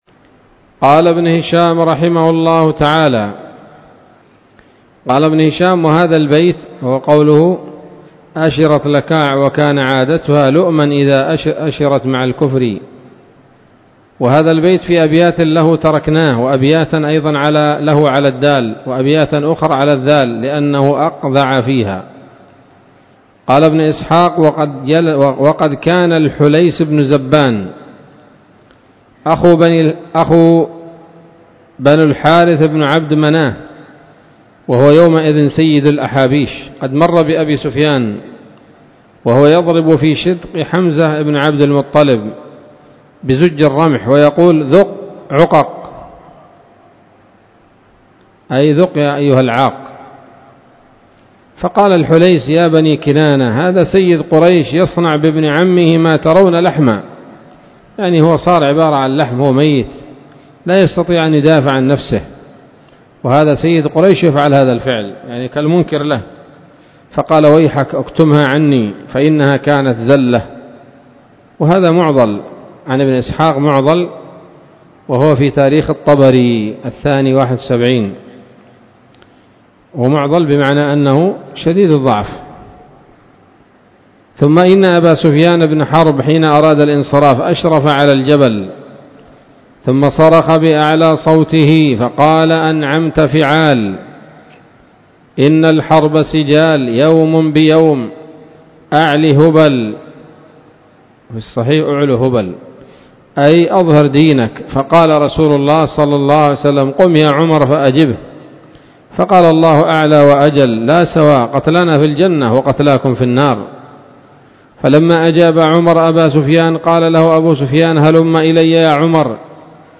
الدرس الخامس والستون بعد المائة من التعليق على كتاب السيرة النبوية لابن هشام